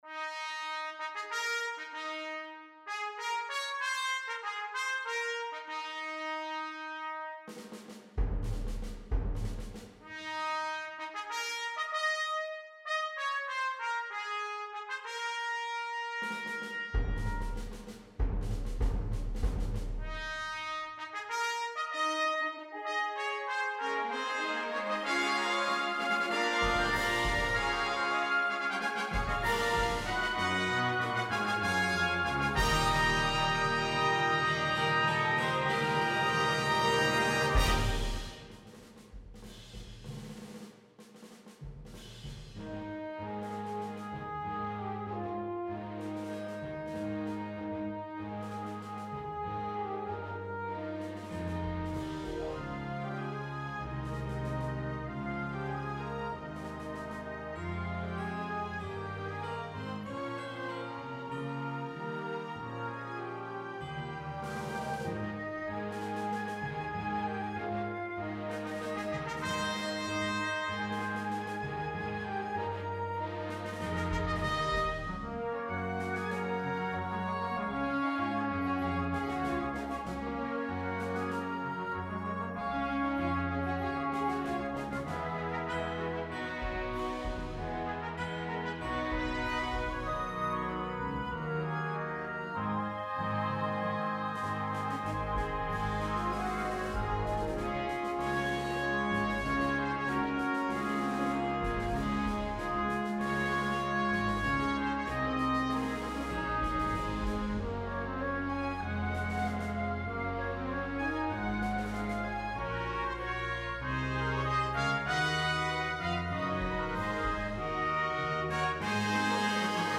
An original, strong patriotic piece.